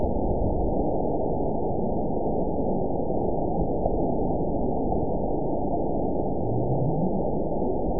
event 920353 date 03/18/24 time 03:18:58 GMT (1 year, 1 month ago) score 9.59 location TSS-AB02 detected by nrw target species NRW annotations +NRW Spectrogram: Frequency (kHz) vs. Time (s) audio not available .wav